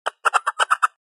Ringetone Egern Lyd
Kategori Dyr
egern-lyd.mp3